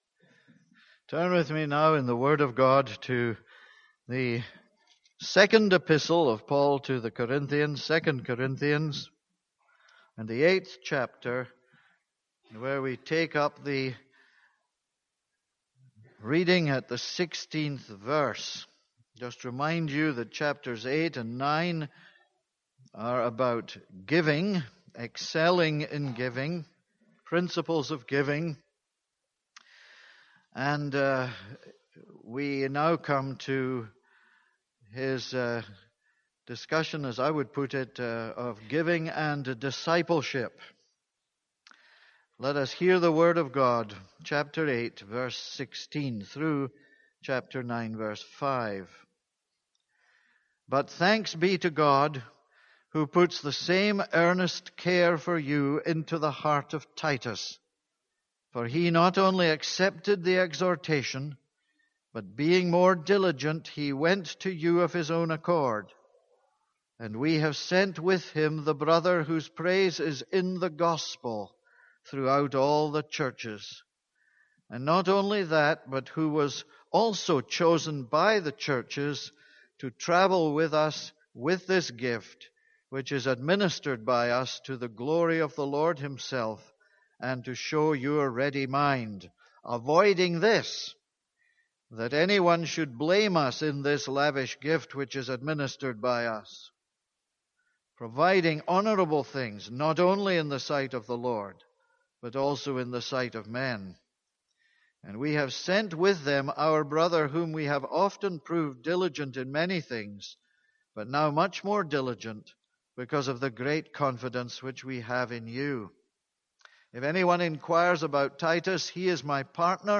This is a sermon on 2 Corinthians 8:16-9:5.